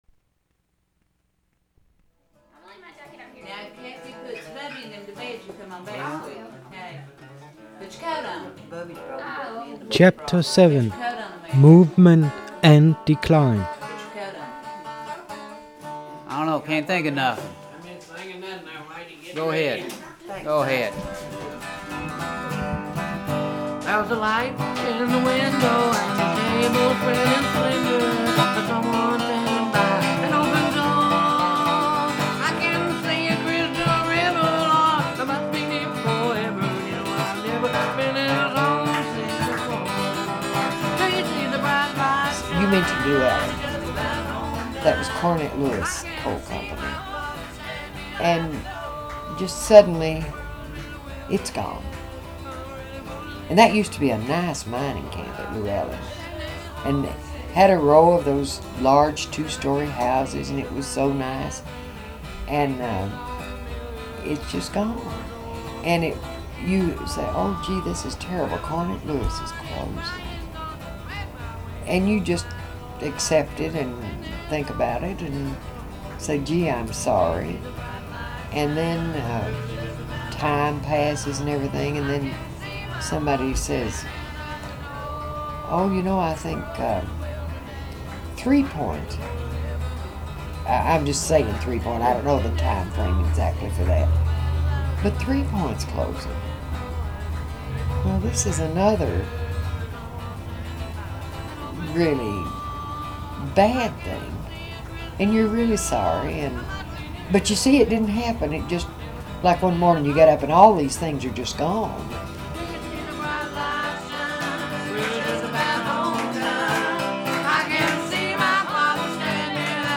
An Essay-In-Sound